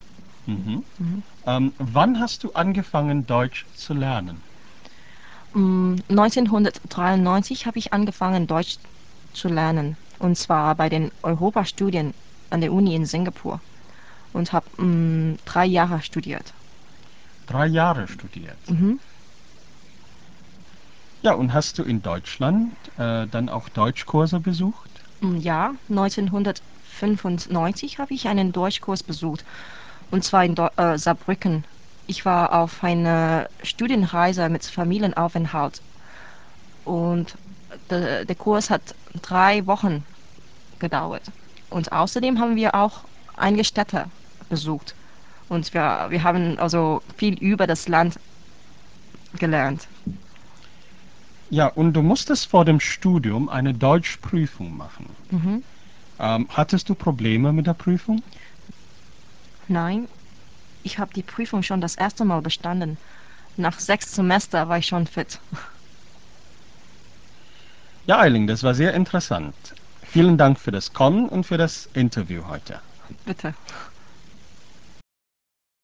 Eine singapurische Studentin über ihre Erfahrungen in Deutschland - Teil I
Hörverständnis